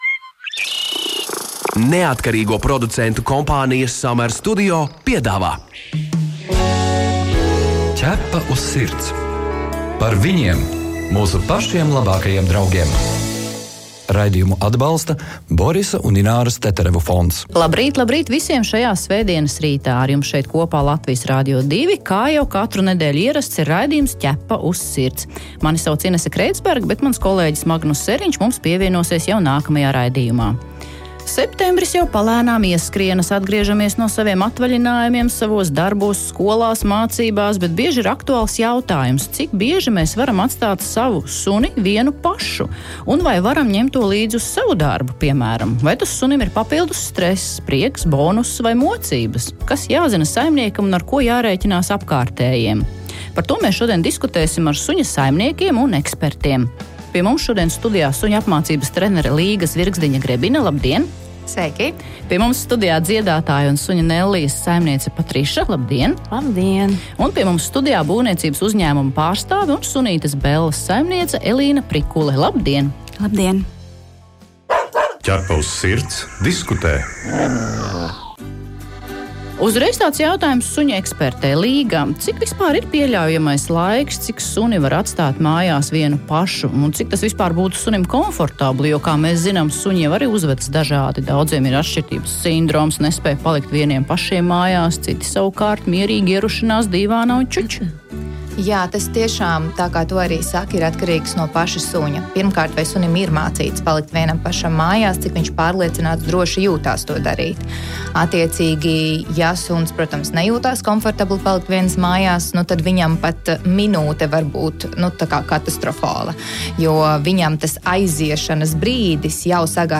Vai tas sunim ir papildus stress, prieks, bonuss vai mocības? Kas jāzina saimniekam un ar ko jārēķinās apkārtējiem? Studijas viesi